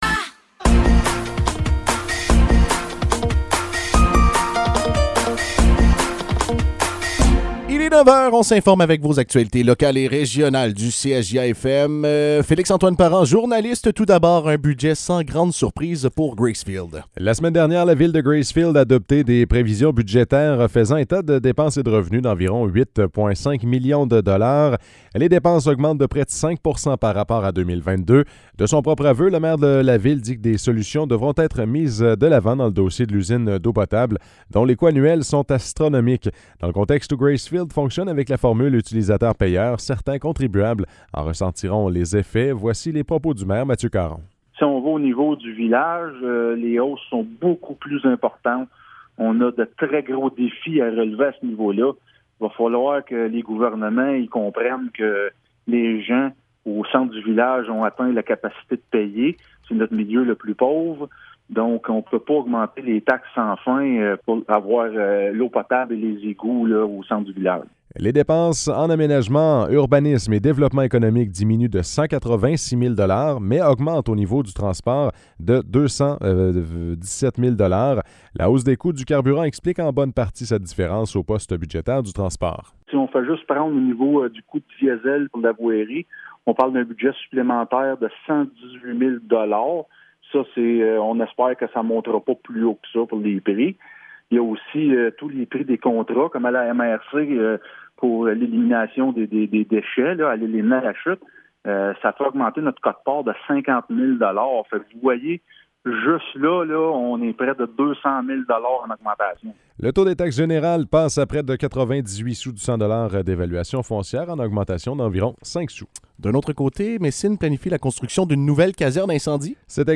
Nouvelles locales - 27 décembre 2022 - 9 h